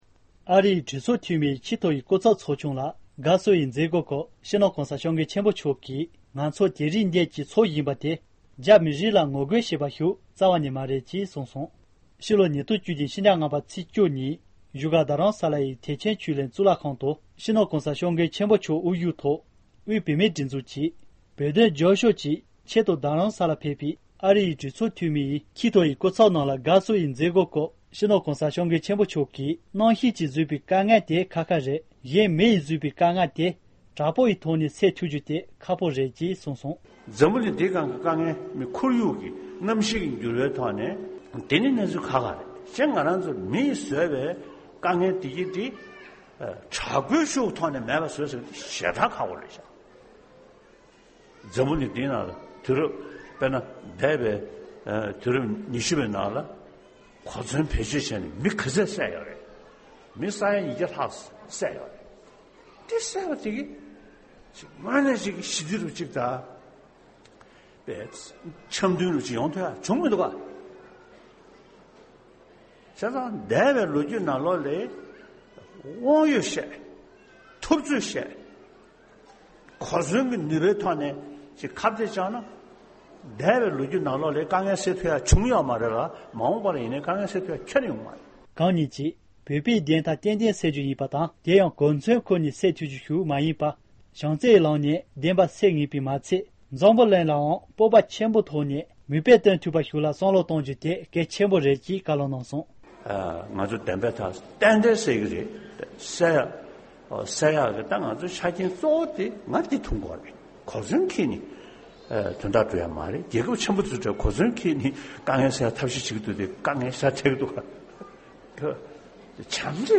བཞུགས་སྒར་དུ་ཨ་རིའི་གྲོས་ཚོགས་ཀྱི་ཆེས་མཐོའི་སྐུ་ཚབ་ཚོཊ་པར་ཕེབས་པའི་དགའ་བསུའི་མཛད་སྒོའི་སྐབས། ༢༠༡༧།༥།༡༠